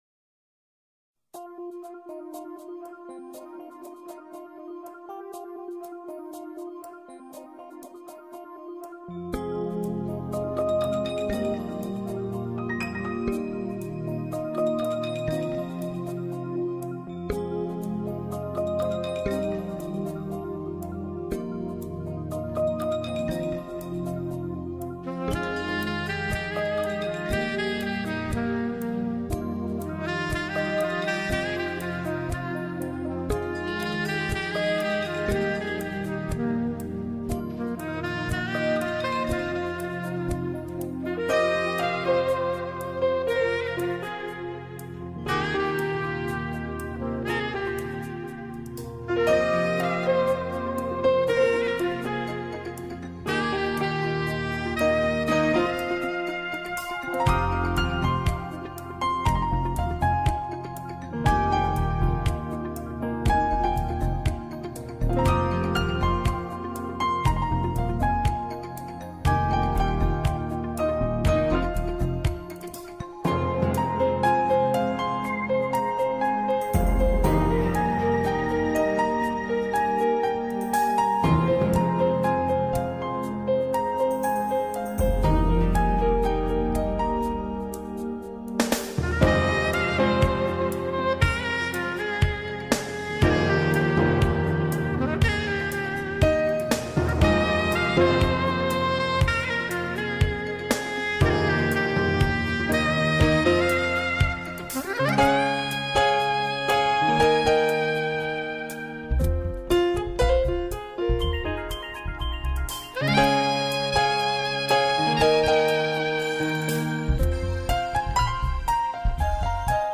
исполняющая музыку в стиле нью эйдж и джаз.